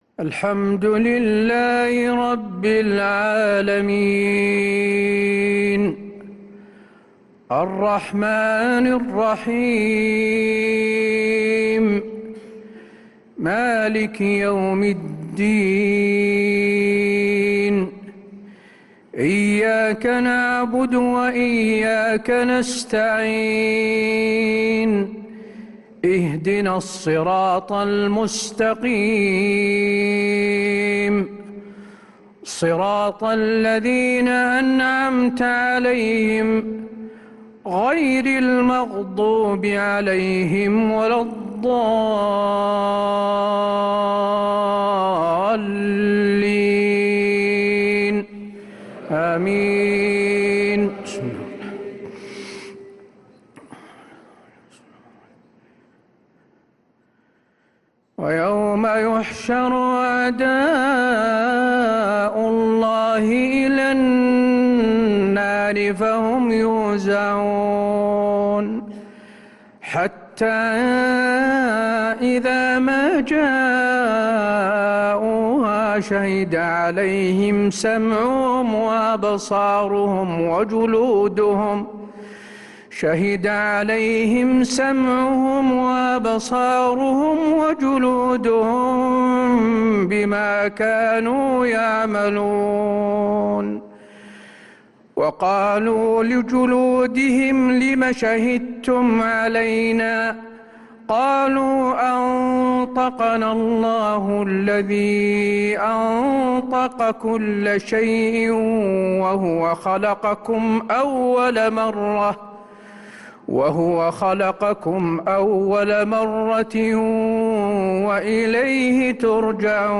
صلاة العشاء للقارئ حسين آل الشيخ 13 ذو القعدة 1445 هـ